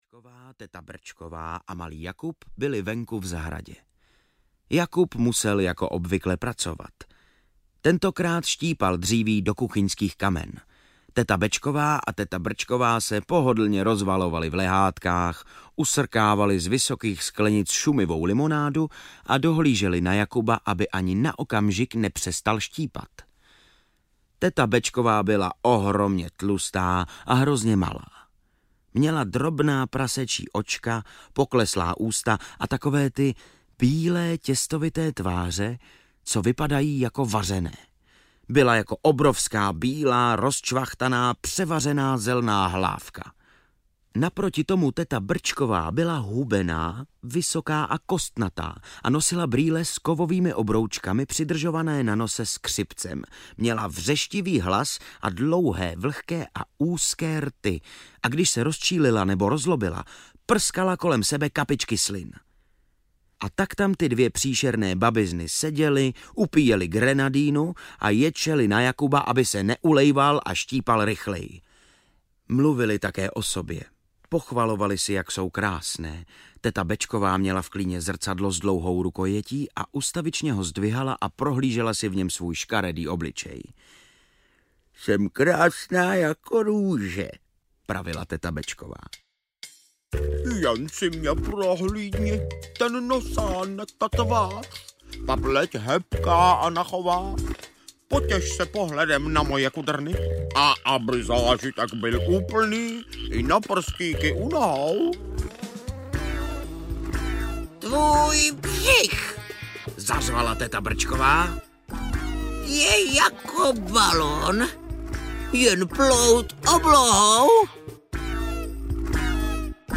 Jakub a obří broskev audiokniha
Ukázka z knihy
• InterpretVojtěch Kotek